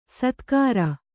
♪ satkāra